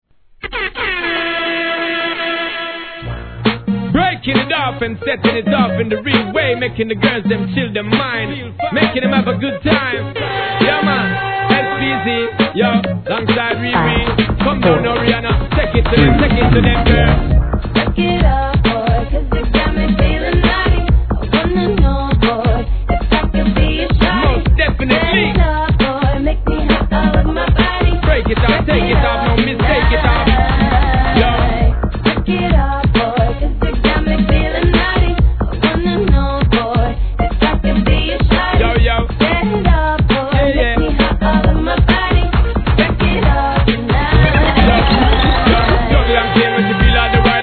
1. HIP HOP/R&B
テンションガチ上げ系でお馴染みス!